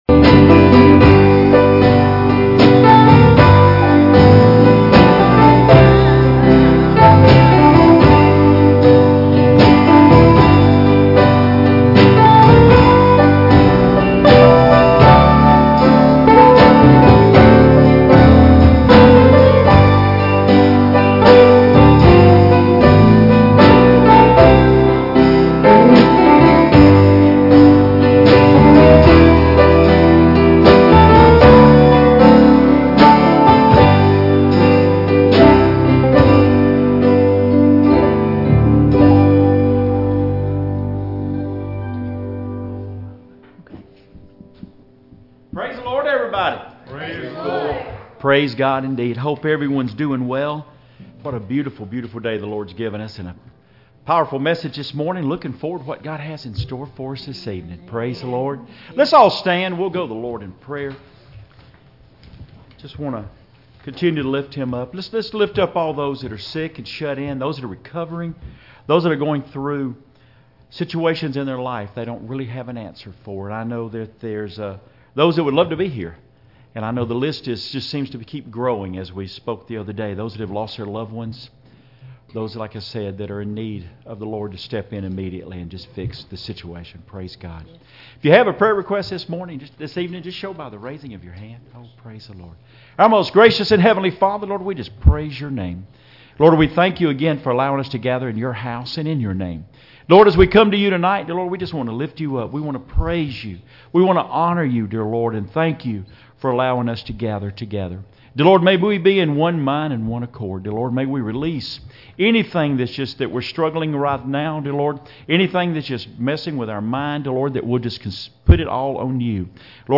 Passage: "Lamentations 4:12-19" Service Type: Sunday Evening Services « “In The Time of Harvest “Why Leave The Father?”